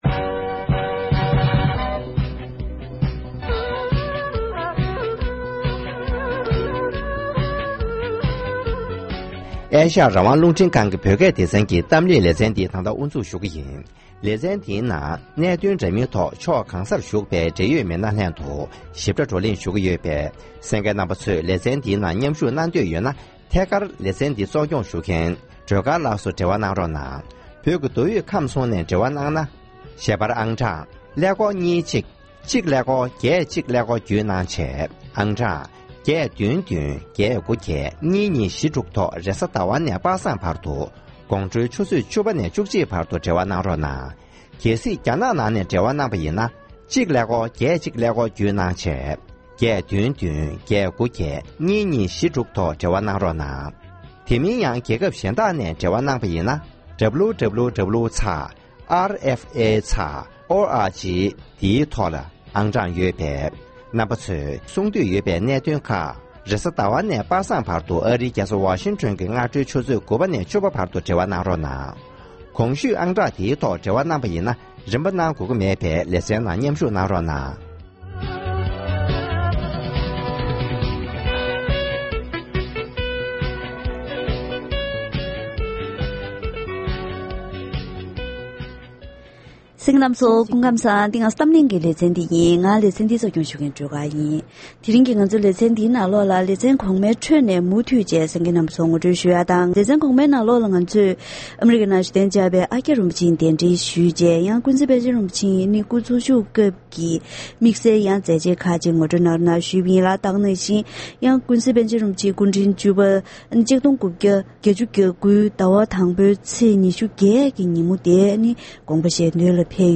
༄༅༎དེ་རིང་གི་གཏམ་གླེང་ལེ་ཚན་ནང་ཀུན་གཟིགས་པཎ་ཆེན་རིན་པོ་ཆེ་སྐུ་ཕྲེང་བཅུ་པ་དགོངས་པ་རྫོགས་ནས་ལོ་ངོ་༢༥འཁོར་བའི་སྐབས་དེར་༸པན་ཆེན་སྐུ་ཕྲེང་བཅུ་པའི་ཡང་སྲིད་ངོས་འཛིན་སྐབས་དངོས་སུ་མཛད་སྒོར་ཞུགས་མྱོང་བའི་ཨཀྱ་རིན་པོ་ཆེས་ཞིབ་ཕྲའི་གནས་ཚུལ་ངོ་སྤྲོད་གནང་བ་དང་།